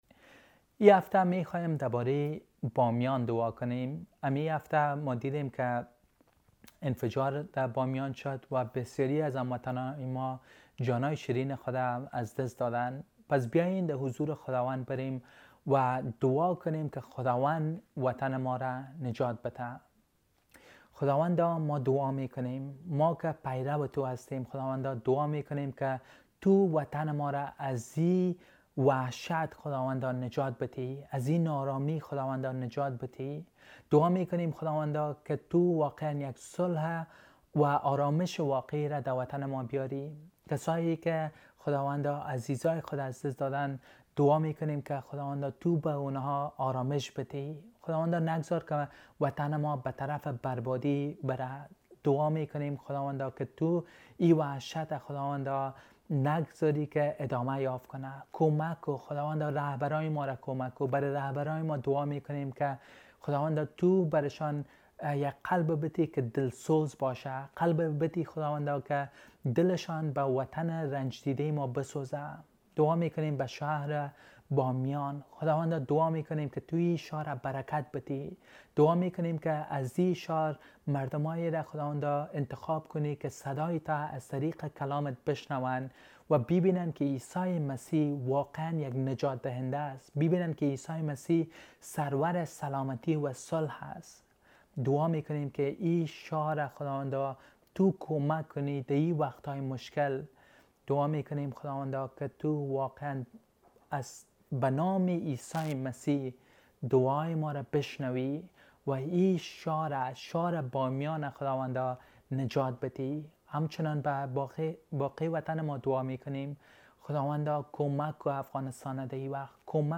Terrorist Attack on the City of Bamyan and Prayer for Bamyan